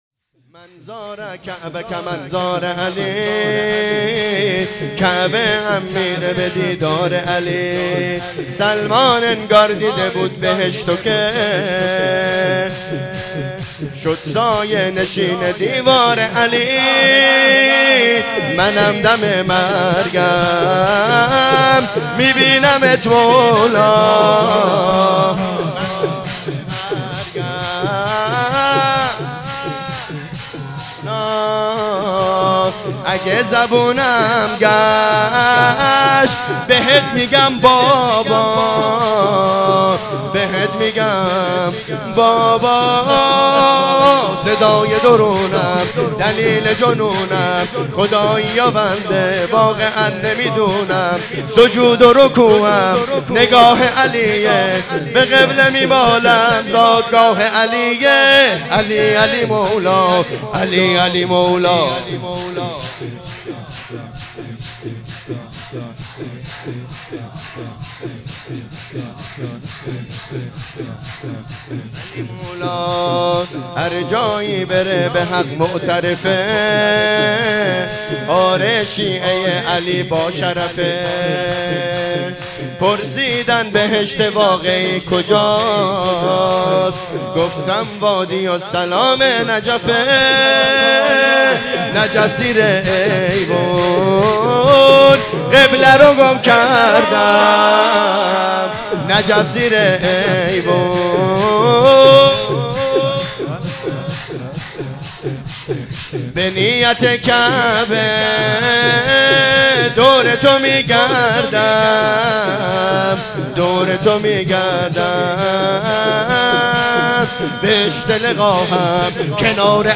جشن ولادت حضرت زهرا سلام الله